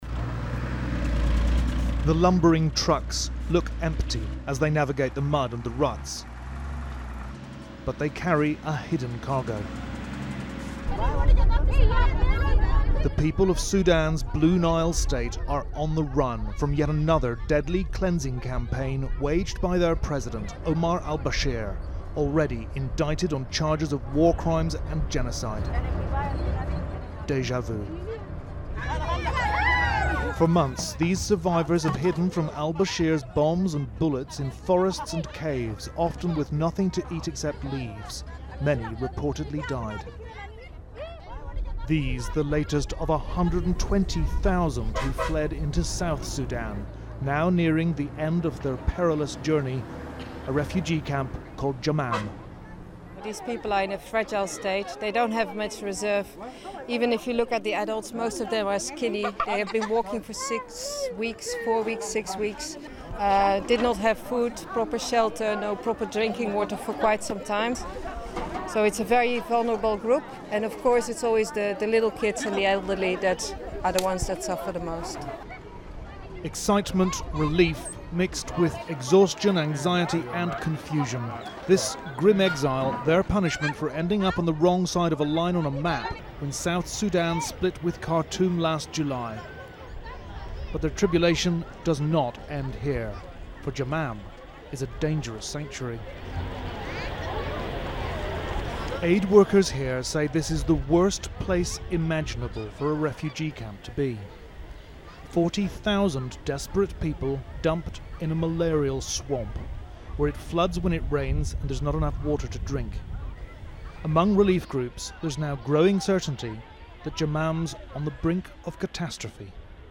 Judy Woodruff reports.